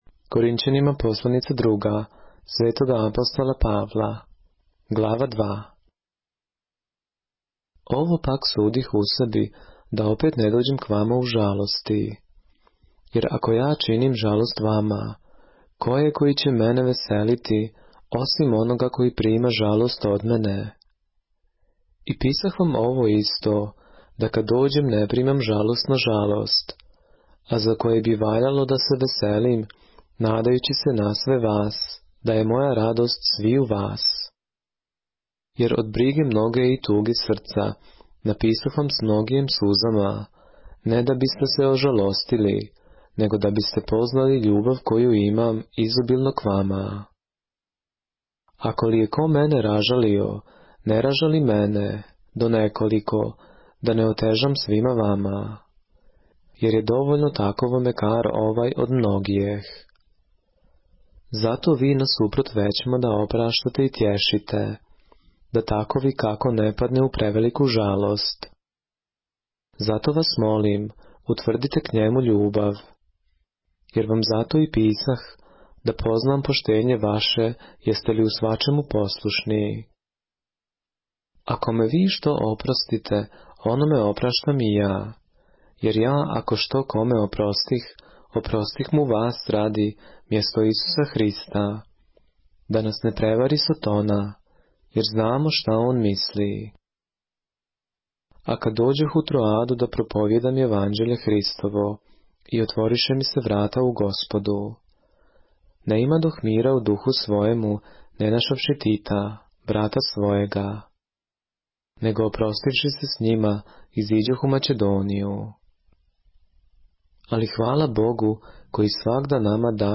поглавље српске Библије - са аудио нарације - 2 Corinthians, chapter 2 of the Holy Bible in the Serbian language